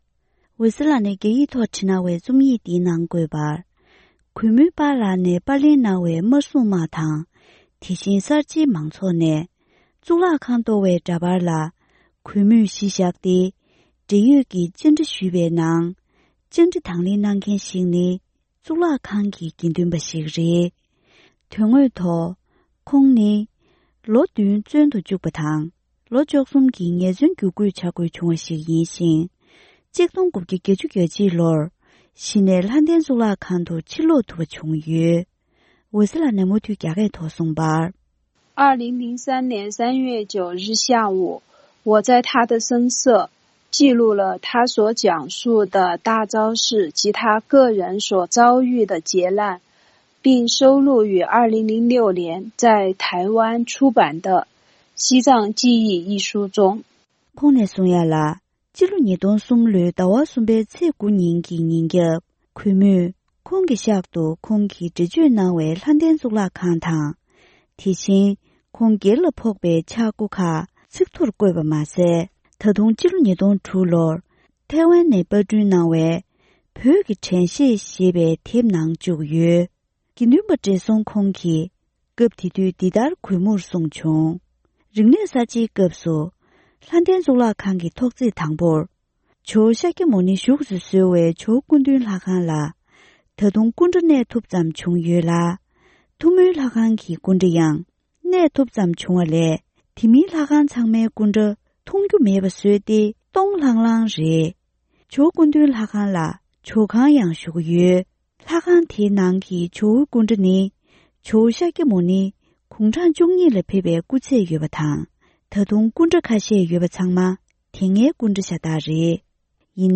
གཙུག་ལག་ཁང་གི་དགེ་འདུན་པ་ཞིག་ལ་བཅར་འདྲི་ཞུས་པ།